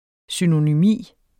Udtale [ synonyˈmiˀ ]